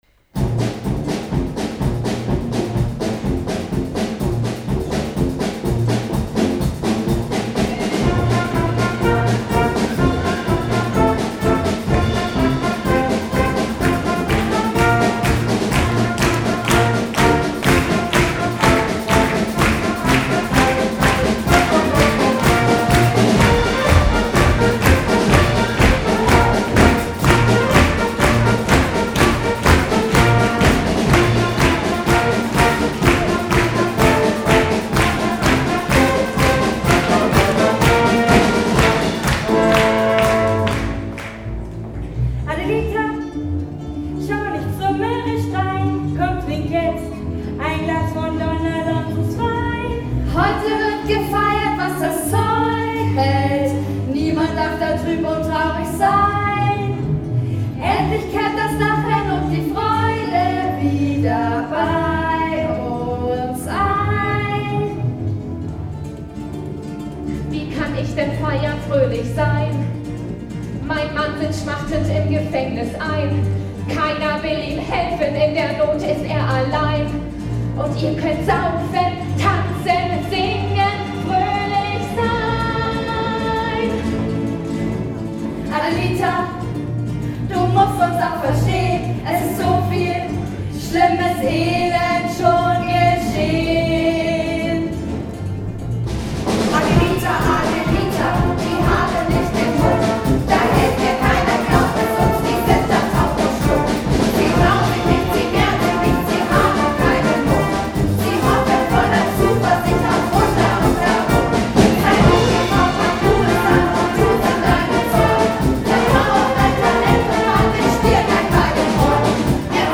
Die Dorfbevölkerung strömt auf den Platz, die Musik beginnt zu spielen, alle feiern ausgelassen.